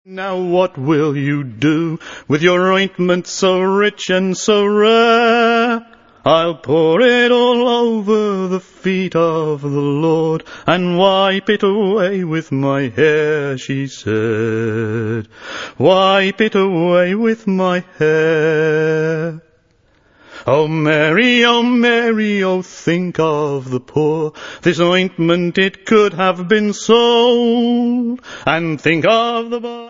Vocals & Guitar
Recorded at Liberty Hall Studios, New Barnet